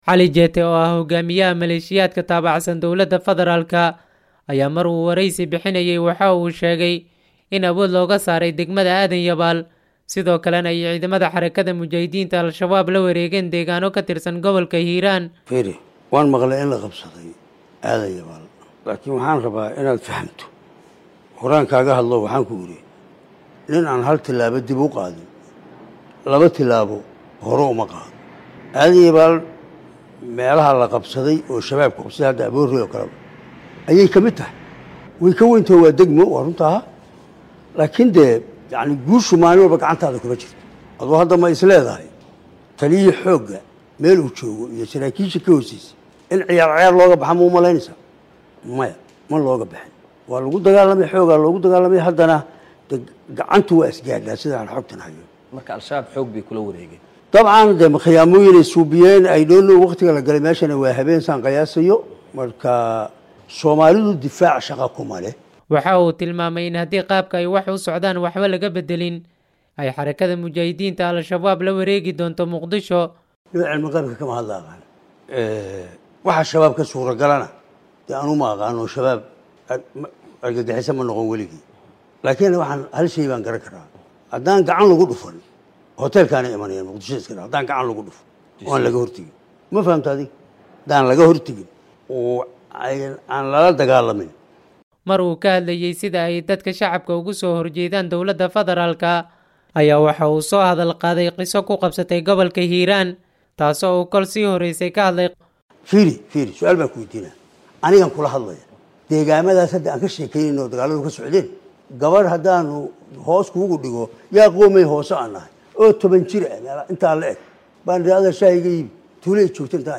Cali Jeyte oo ah hogaamiyaha Maleeshiyaadka taabacsan Dowladda Fedaraalka, ayaa mar uu wareysi bixinayay waxa uu sheegay in awood looga soo saaray degmada Aadan Yabaal, sidoo kalana ay ciidamada Xarakada Mujaahidiinta Al-Shabaab la wareegeen deegaanno katirsan gobalka Hiiraan.